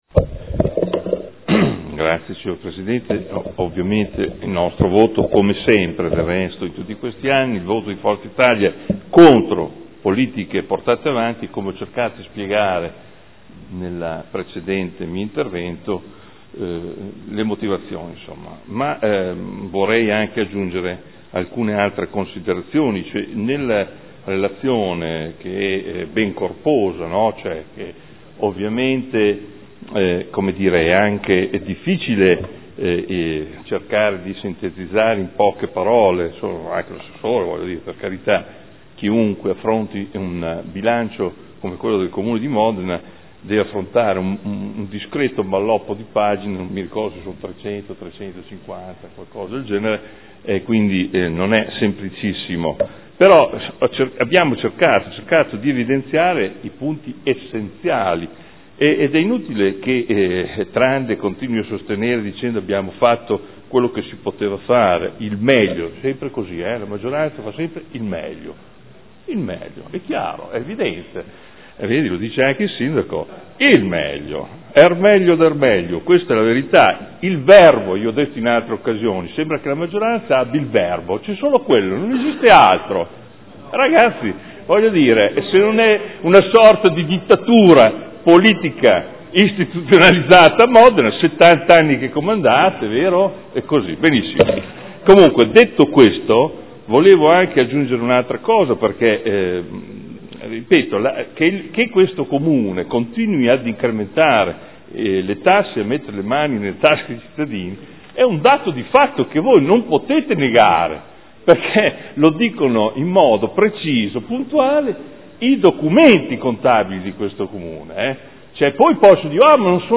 Seduta del 30/04/2015 Dichiarazione di voto. Rendiconto della gestione del Comune di Modena per l’Esercizio 2014 – Approvazione